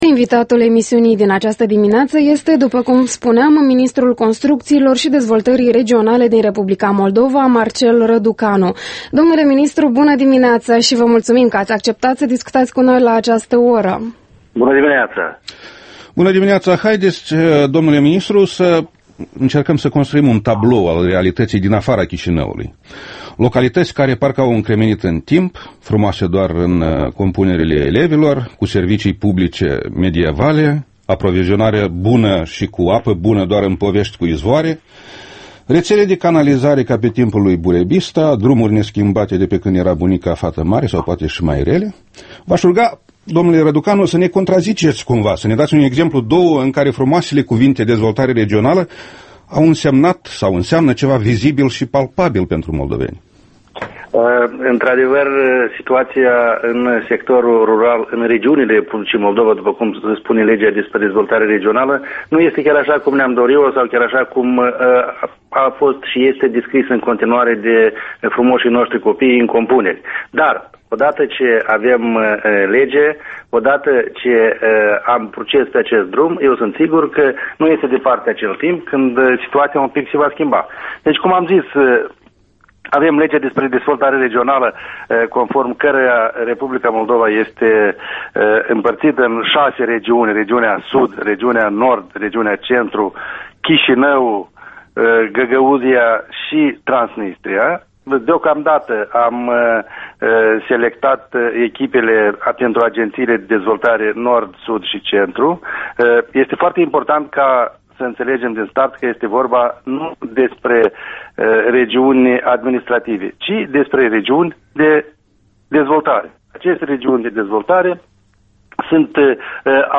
Interviul matinal EL: cu Marcel Răducanu